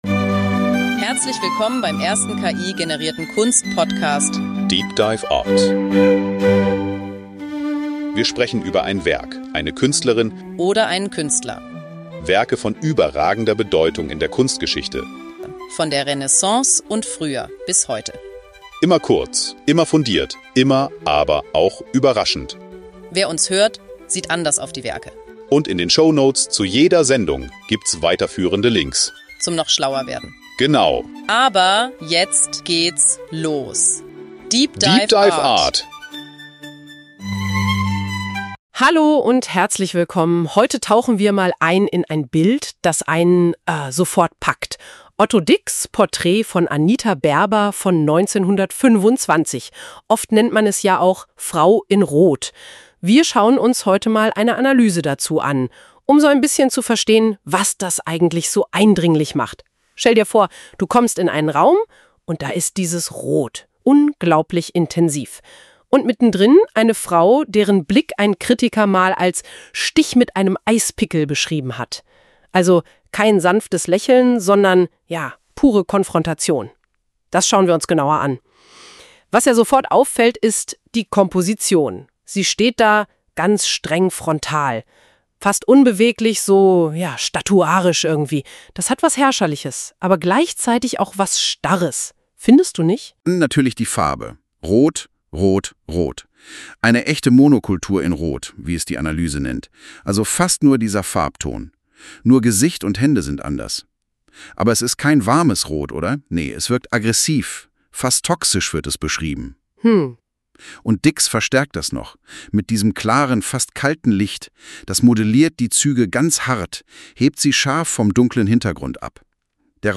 Die beiden Hosts, die Musik, das Episodenfoto,